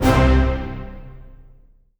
ORChit48(L).wav